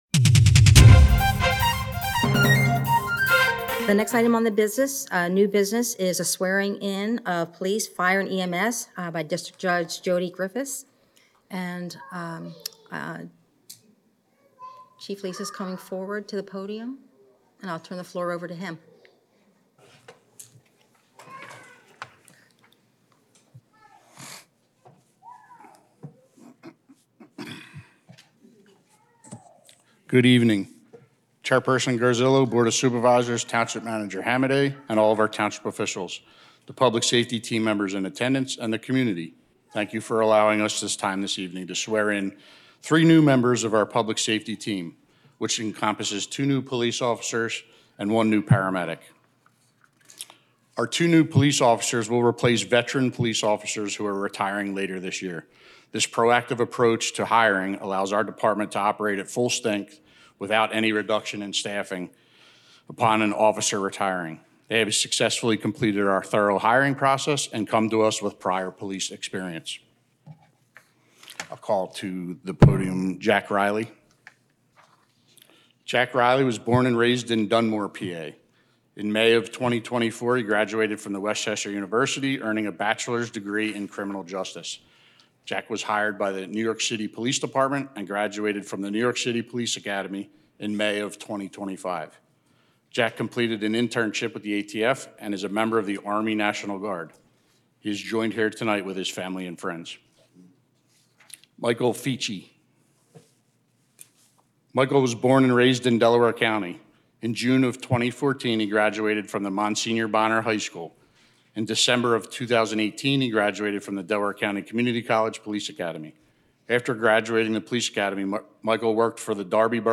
Swearing-In of Police, Fire & EMS by District Judge Jodi Griffis